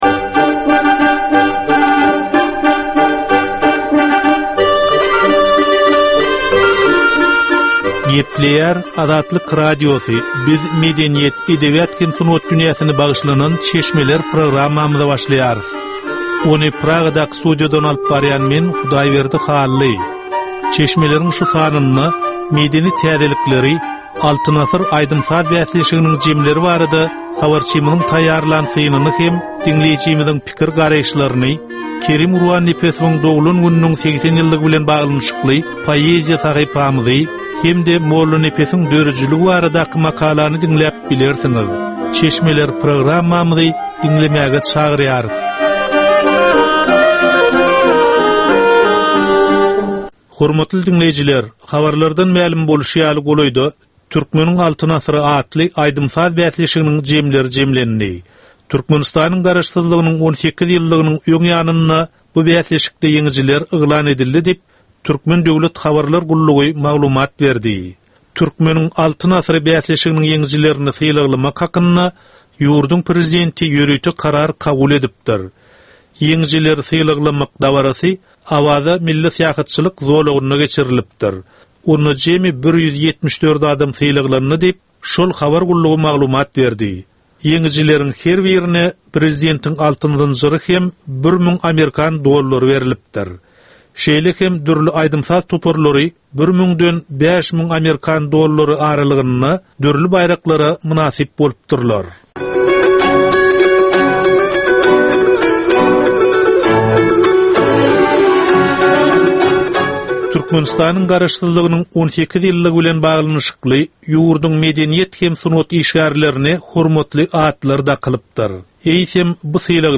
Edebi, medeni we taryhy temalardan 25 minutlyk ýörite gepleşik.